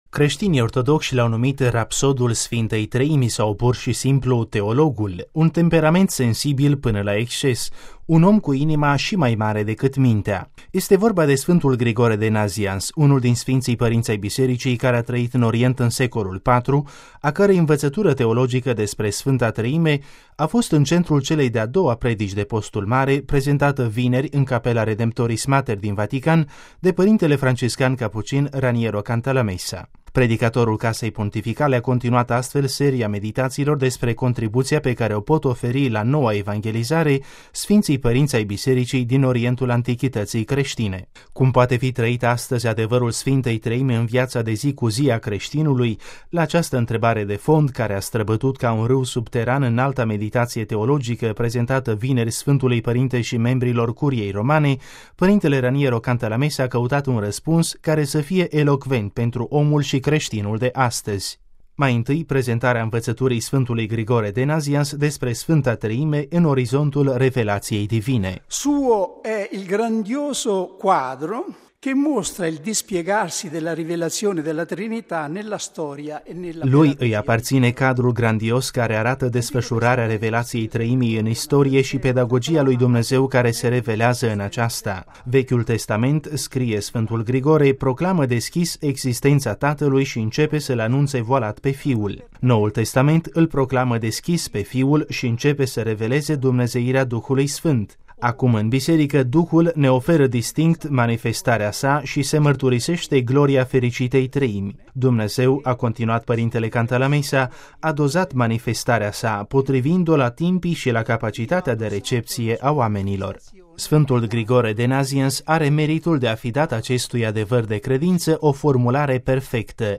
Cristos, poarta de intrare în misterul Sfintei Treimi: actulitatea învăţăturii teologice a Sf. Grigore de Nazians, în a doua predică de Postul Mare a pr. R. Cantalamessa